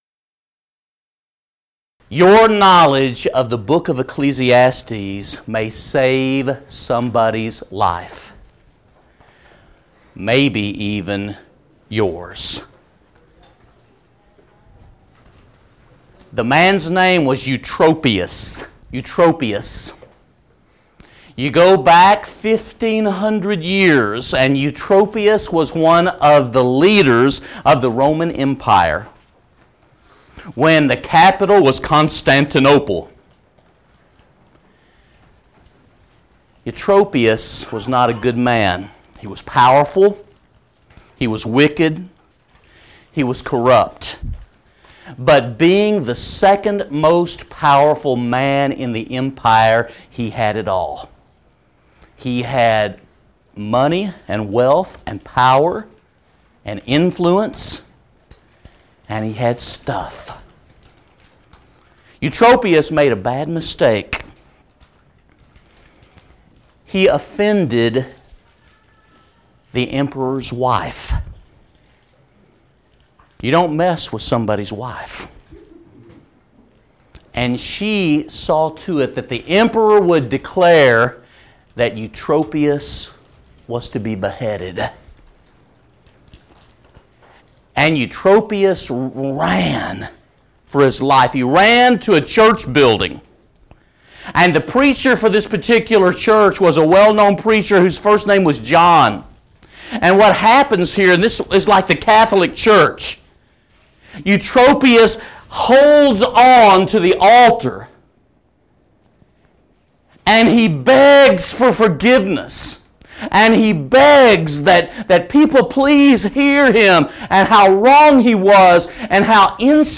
If you would like to order audio or video copies of this lecture